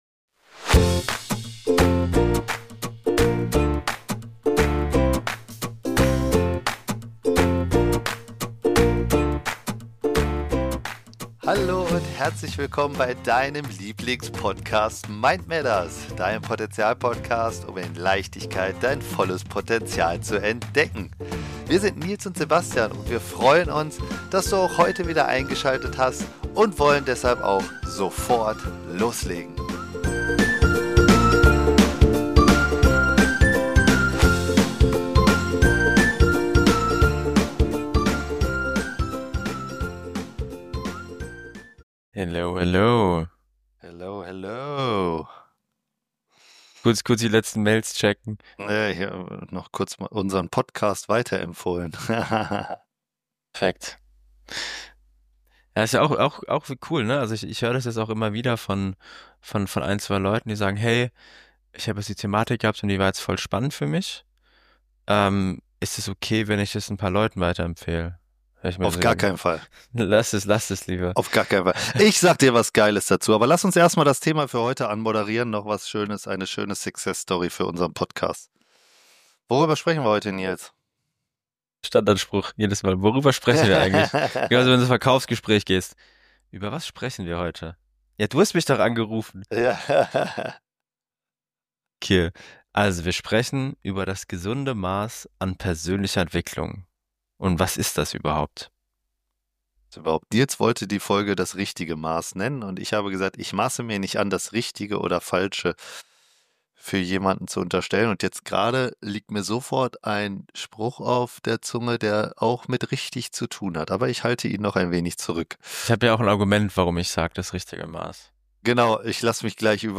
Die Verantwortung für die eigene Entwicklung und die Suche nach dem persönlichen Weg stehen im Mittelpunkt der Gespräche. Die beiden Moderatoren teilen ihre Erfahrungen und ermutigen die Zuhörer, aktiv an ihrer Selbstverwirklichung zu arbeiten.